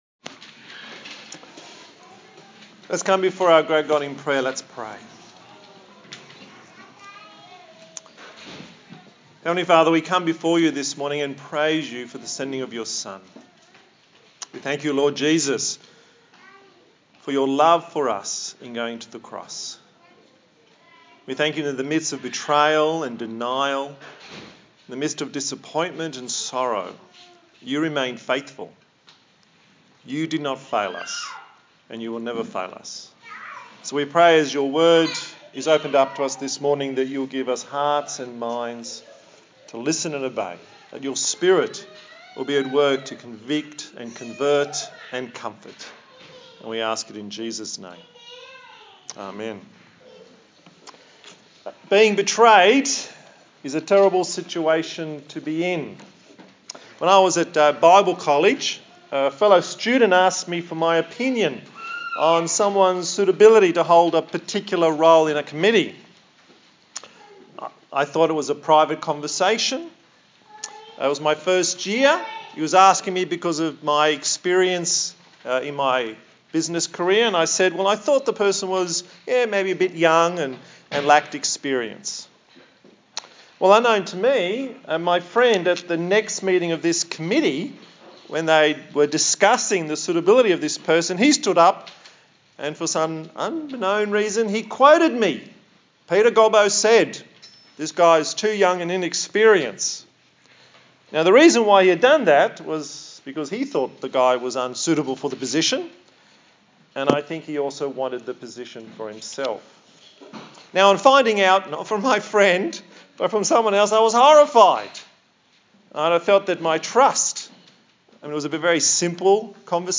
A sermon
Service Type: Sunday Morning